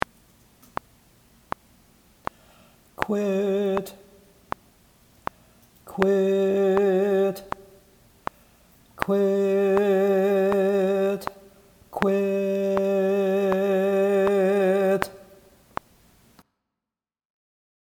It’s easy; all you have to do is sing the terminal consonant (the “t” of “quit”) right on the rest.
Cutoffs, terminal consonant
cutoffs-terminal-consonant.mp3